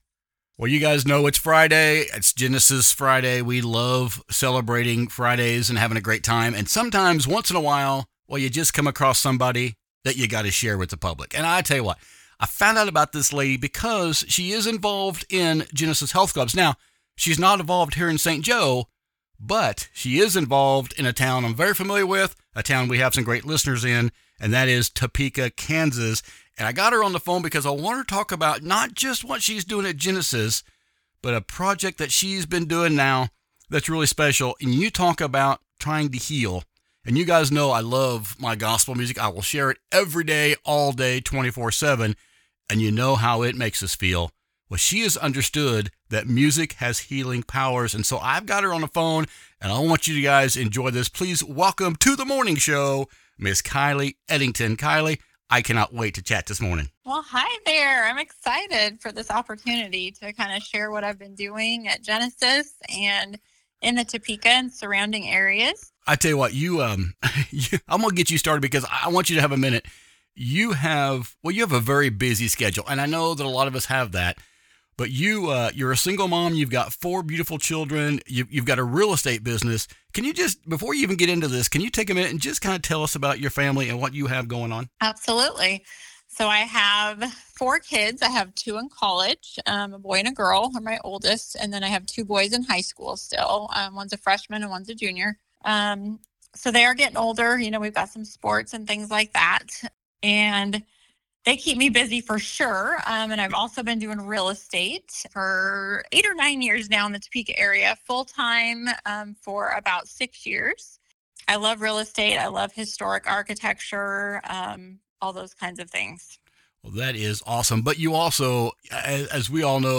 Recent Interviews